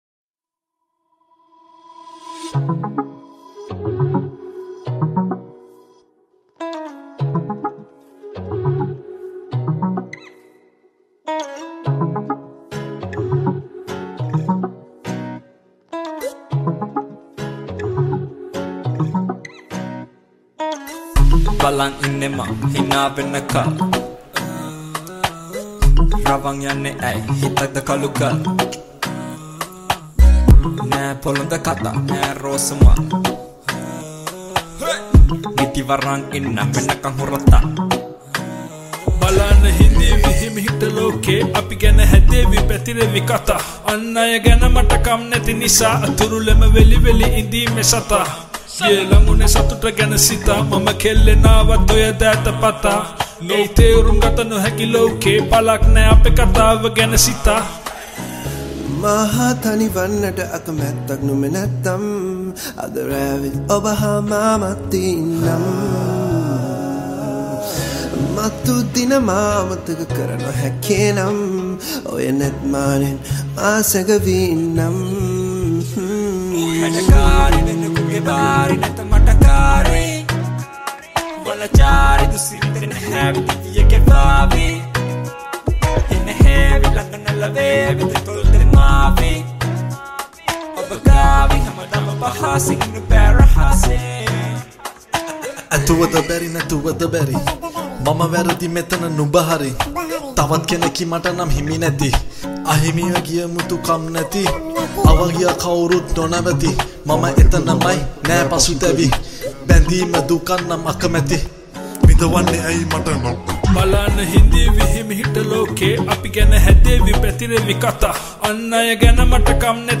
High quality Sri Lankan remix MP3 (3).
Rap